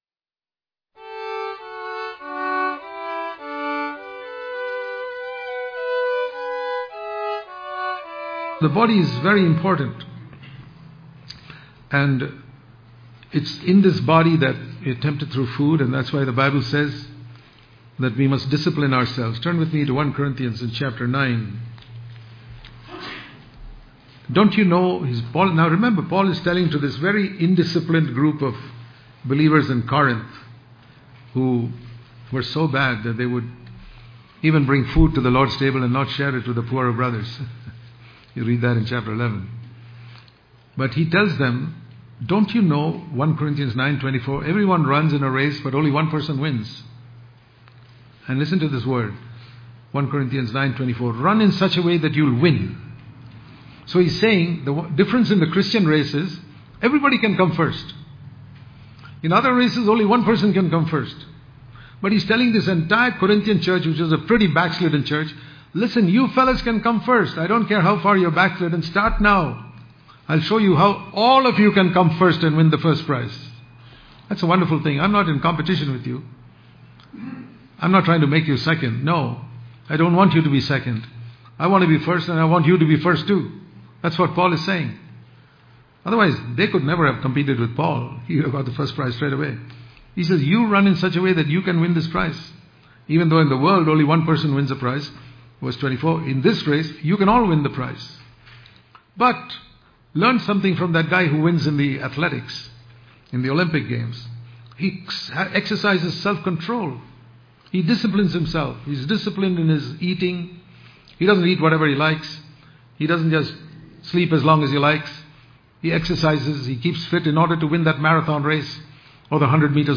March 21 | Daily Devotion | Disciplining Our Body Is Very Important To Run The Christian Race Daily Devotion